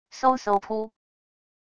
嗖嗖~~噗wav音频